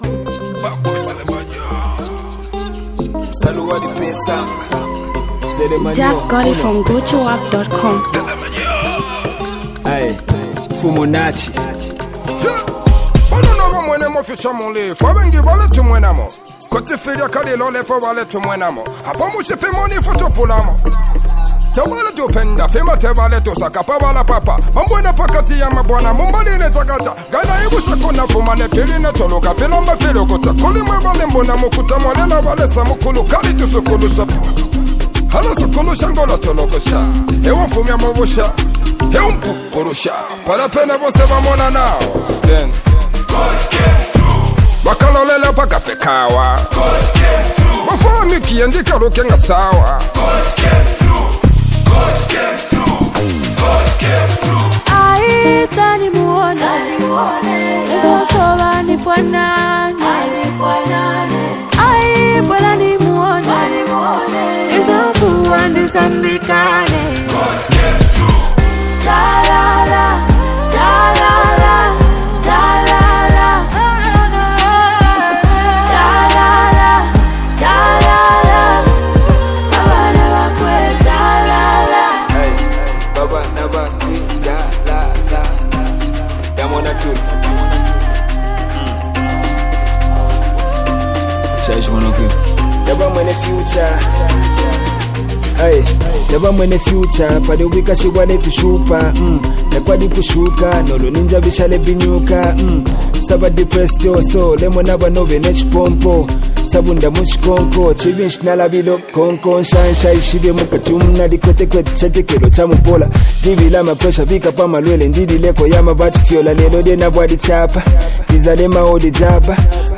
creates a unique sound that’s both energetic and authentic